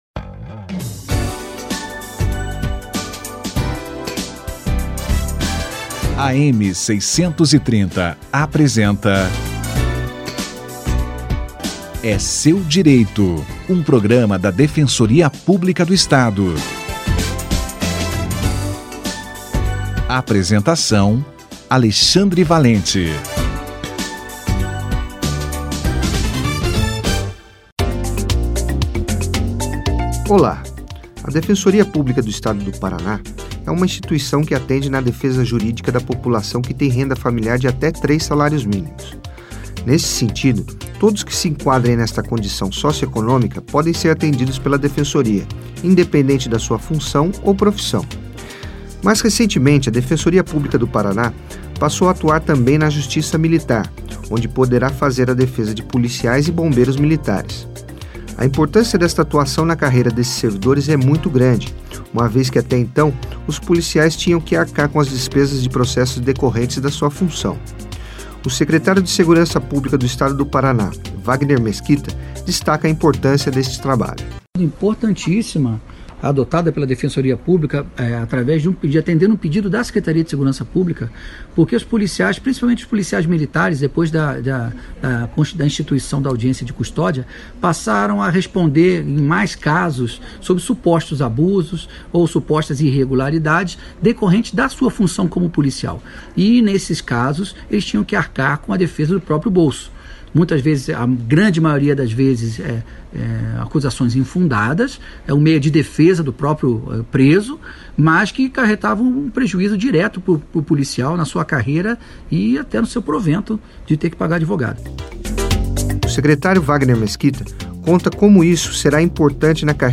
Atuação da Defensoria Pública na Justiça Militar - Entrevista